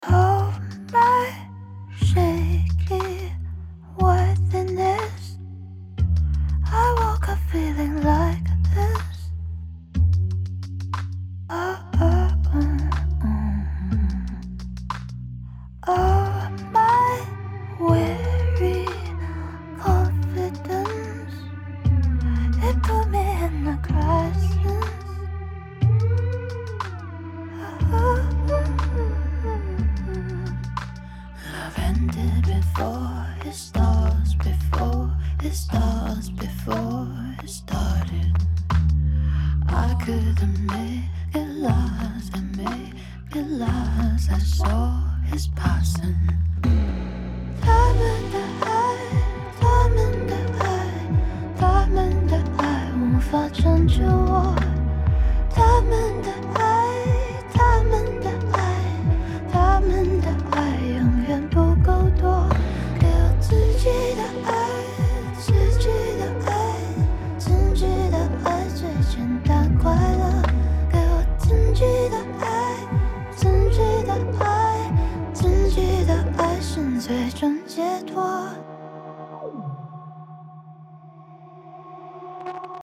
Down Tempo